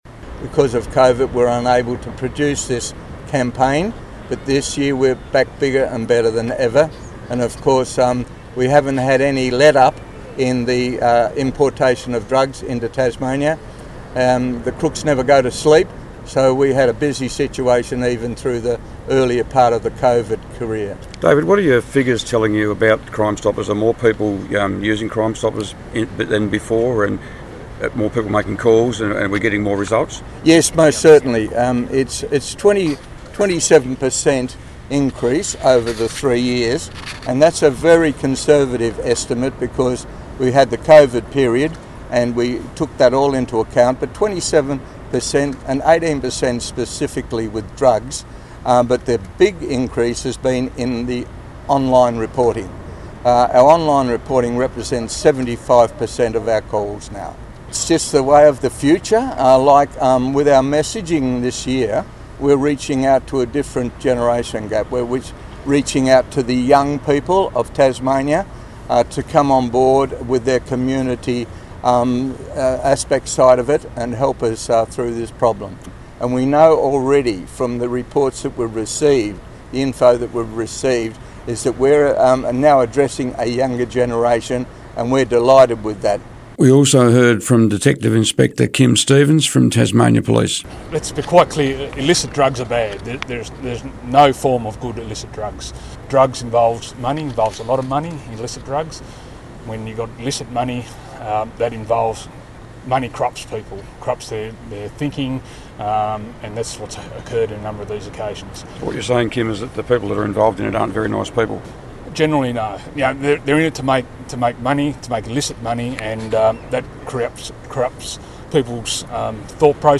at the launch of Crimestoppers Week earlier today